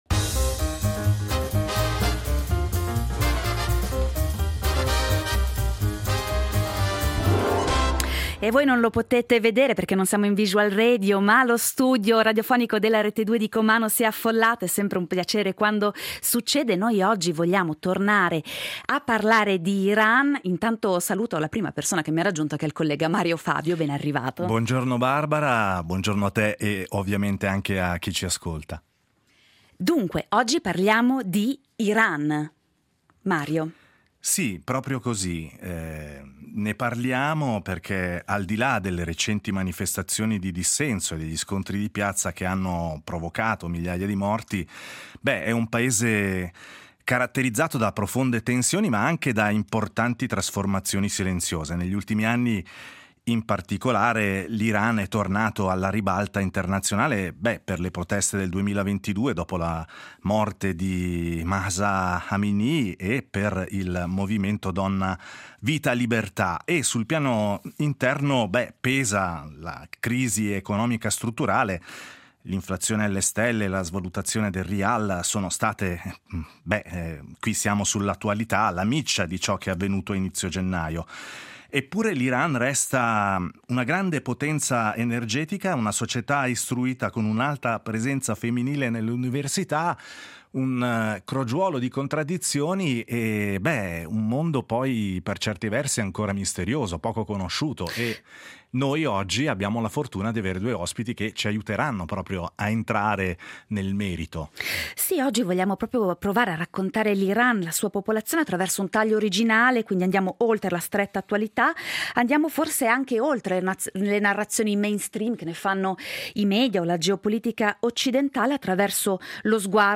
due Iraniani residenti nella Svizzera italiana